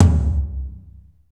TOM F T L0TL.wav